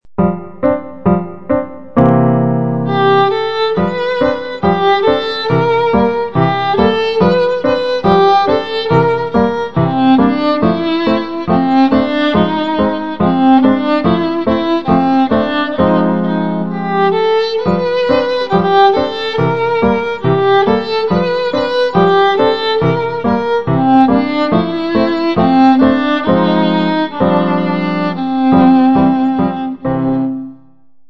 Besetzung: Viola